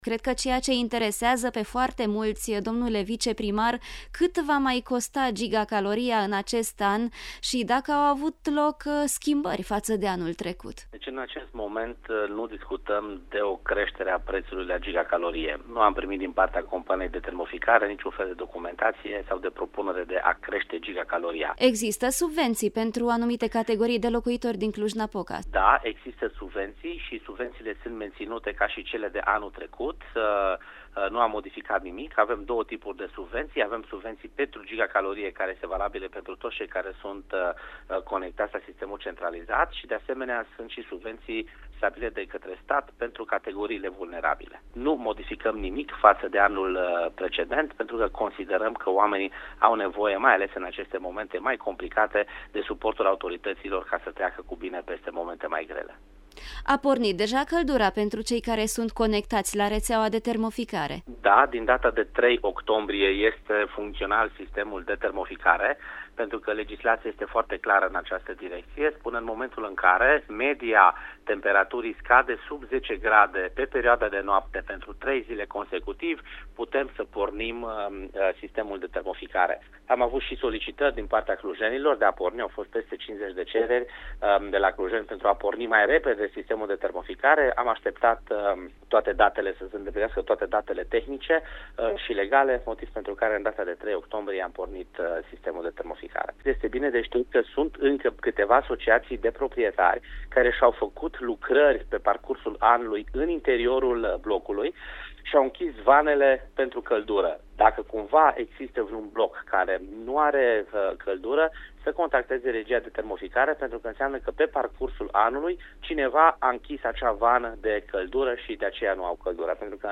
Preţul gigacaloriei la Regia Autonomă de Termoficare din Cluj-Napoca rămâne la acelaşi nivel ca anul trecut, anunţă viceprimarul Dan Tarcea:
Tarcea-termoficare-8-octombrie.mp3